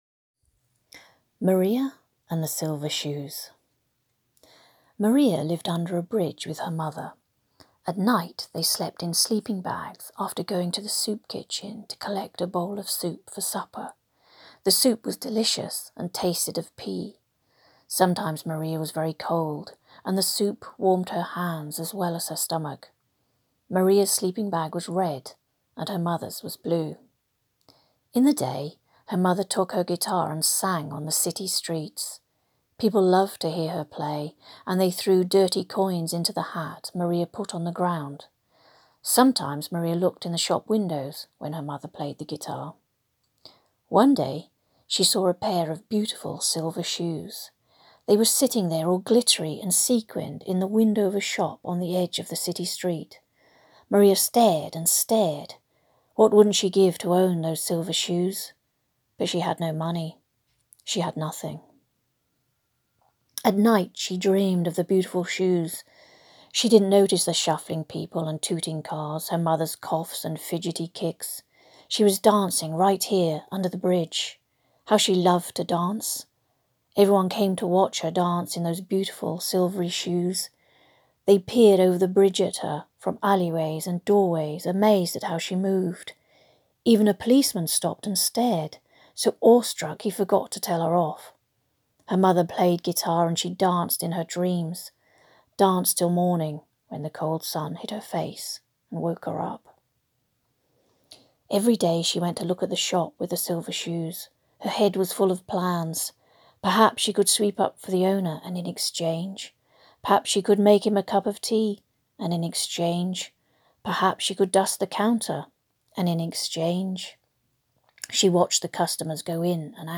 NEW Short Stories 📗📘📙 Watch and Listen to the authors reading their short stories.